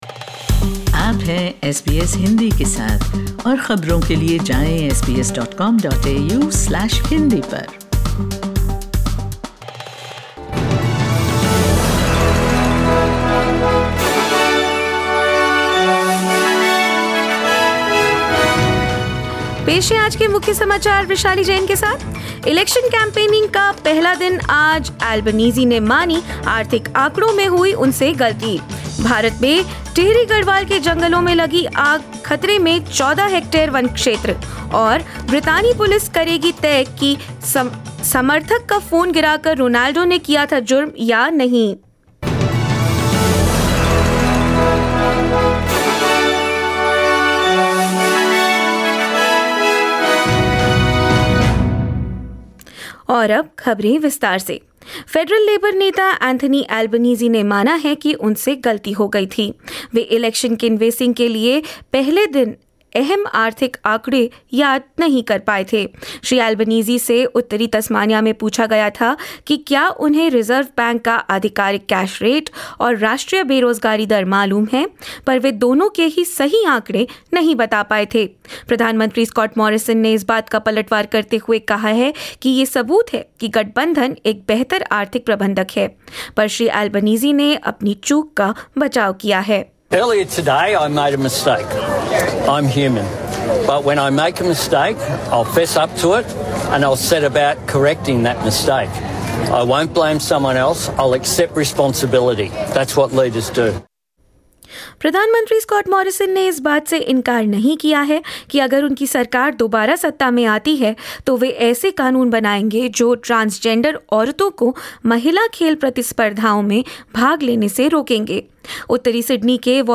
SBS Hindi News 11 April 2022: As the election campaign begins, Anthony Albanese admits to economic figures mistake